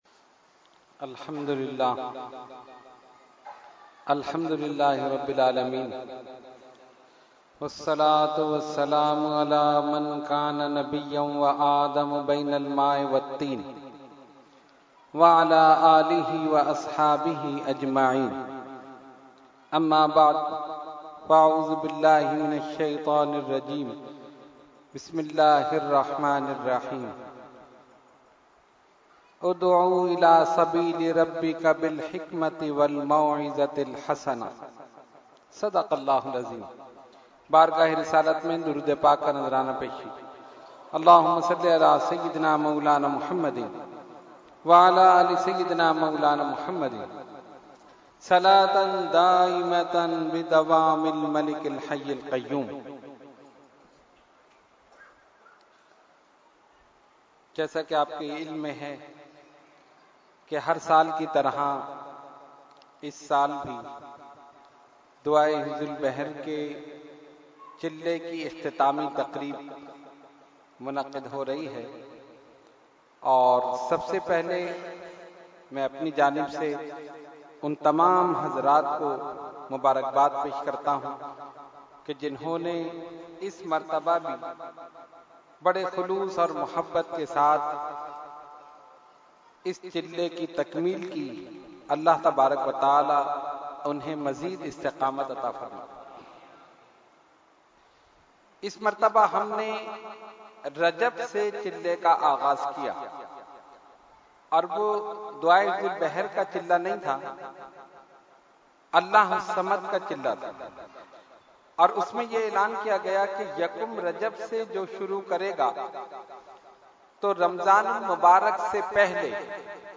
Category : Speech | Language : UrduEvent : Khatam Hizbul Bahr 2017